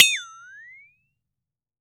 FLEXATONE  5.WAV